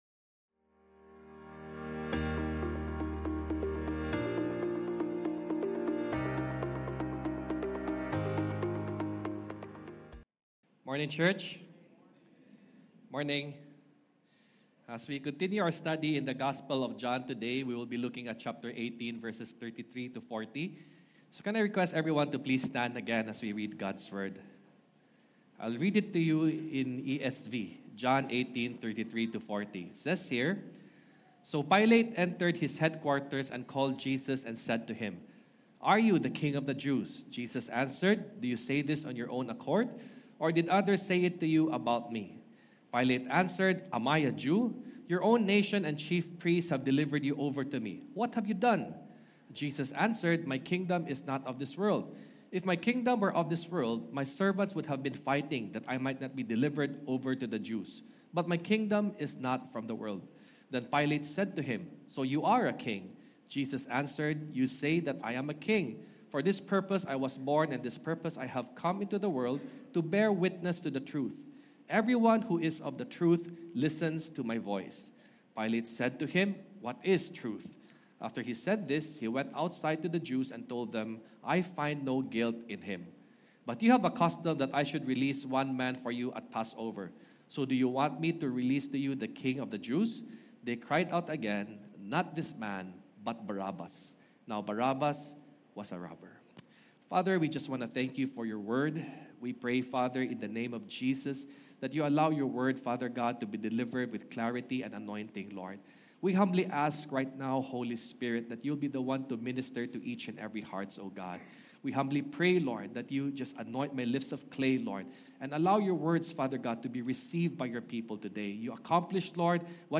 English-Service-June-8-9am-96kbps.mp3